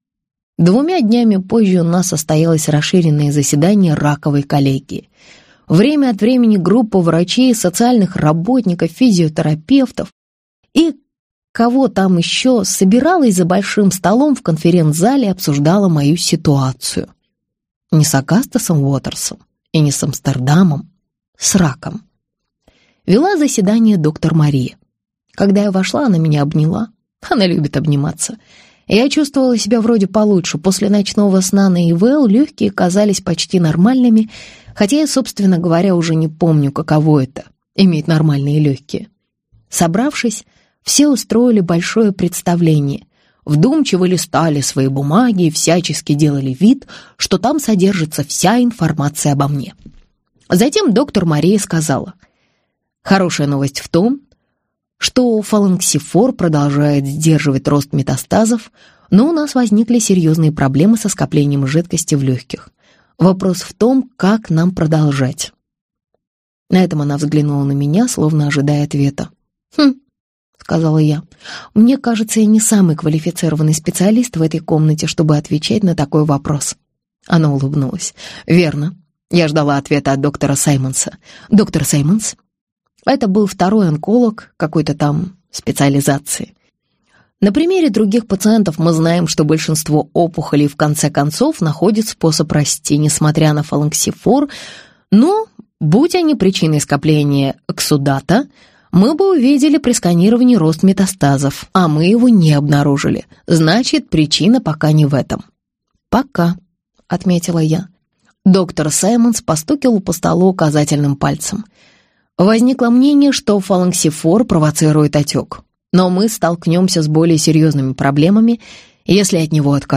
Аудиокнига Виноваты звезды | Библиотека аудиокниг